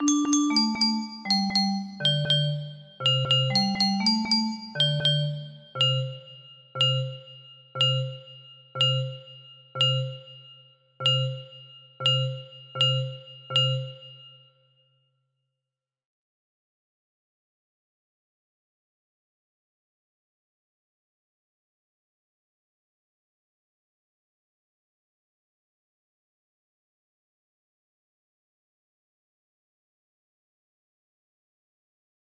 Unknown Artist - west minster chimes @10:00 am music box melody